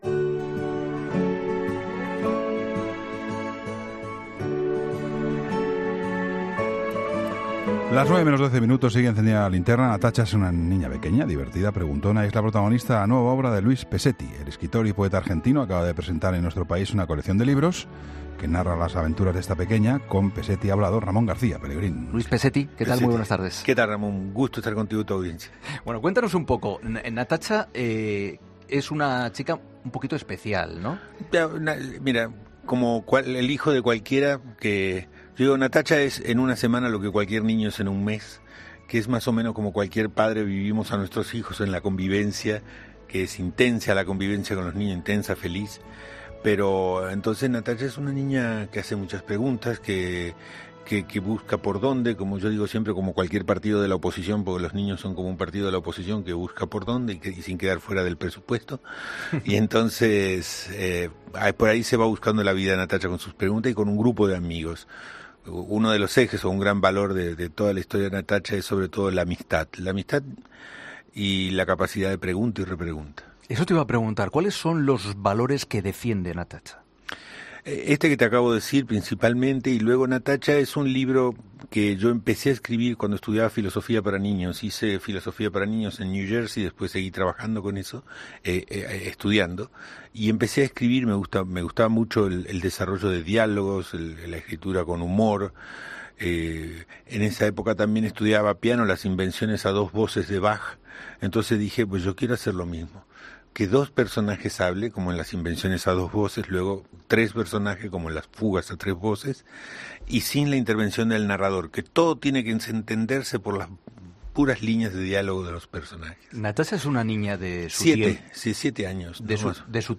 Entrevista con Luis Pescetti, escritor y autor de 'Natacha'